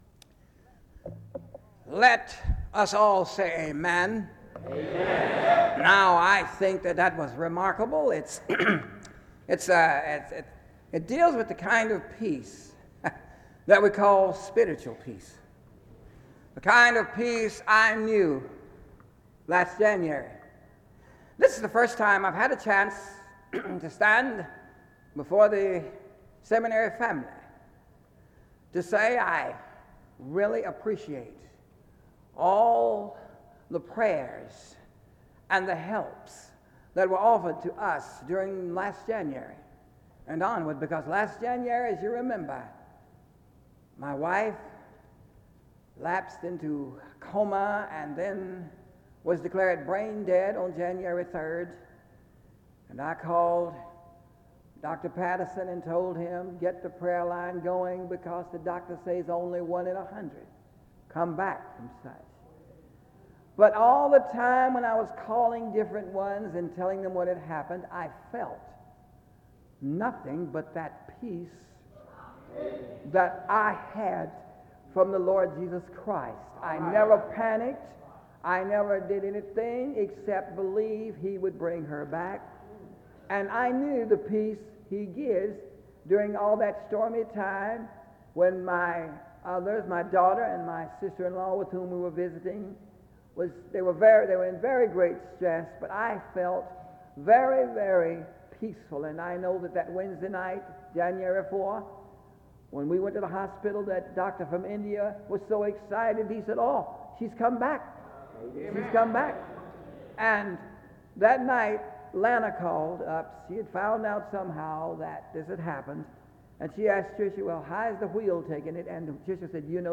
Preaching
SEBTS Chapel and Special Event Recordings SEBTS Chapel and Special Event Recordings